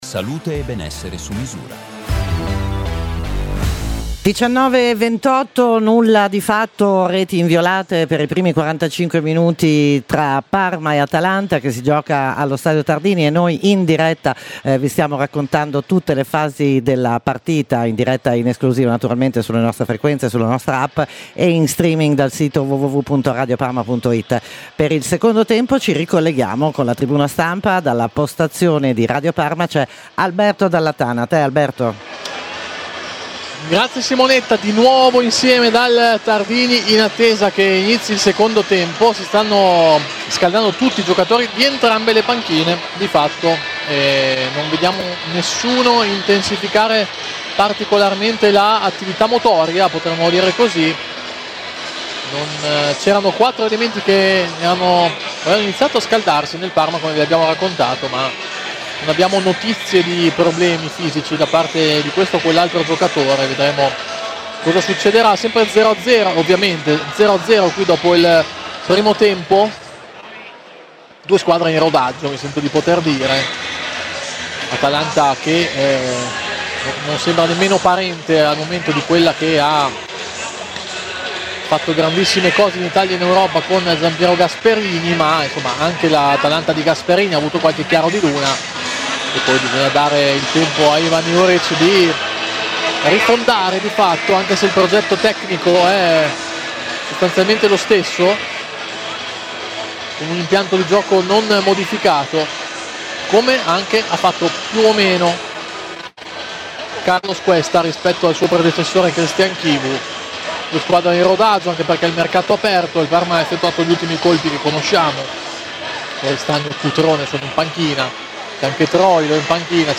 Radiocronache Parma Calcio Parma - Atalanta 2° tempo 30 agosto 2025 Aug 30 2025 | 00:54:45 1x 00:00 / 00:54:45 Subscribe Share RSS Feed Share Link Embed